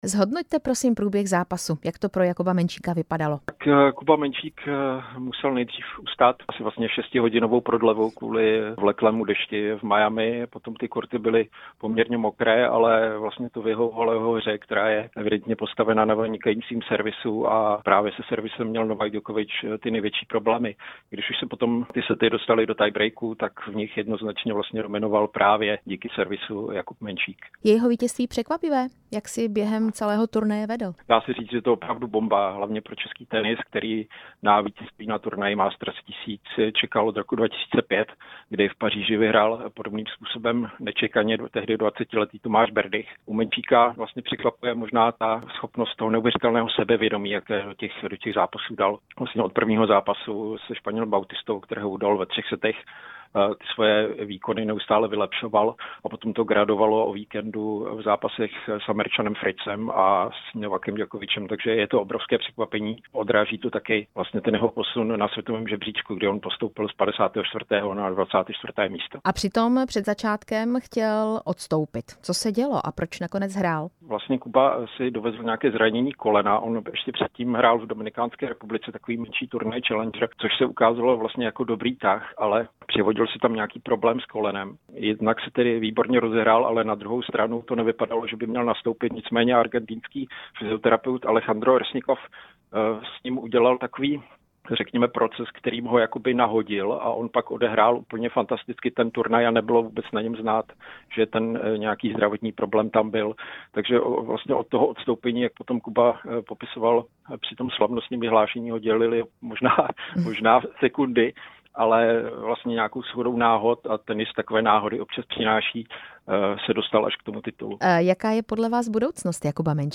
Rozhovor se sportovním novinářem